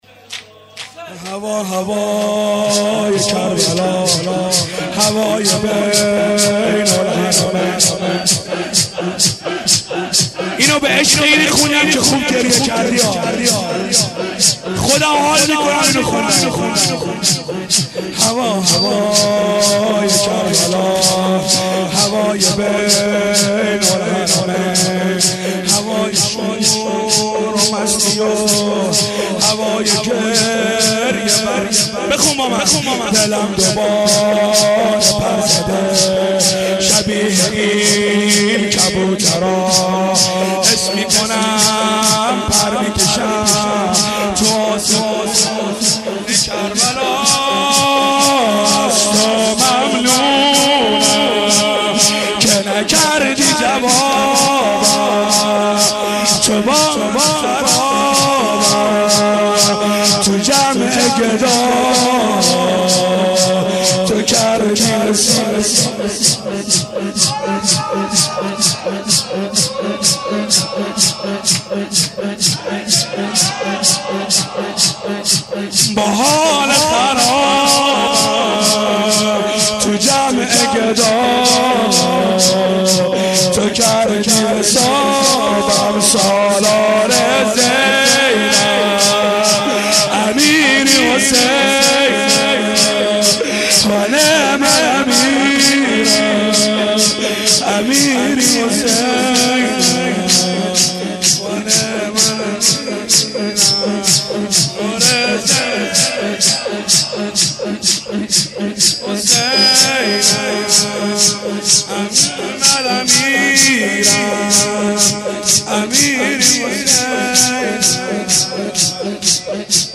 هوا هوای کربلا ...(سینه زنی/شور) هیئت بیت الاحزان حضرت زهرا (س)/اهواز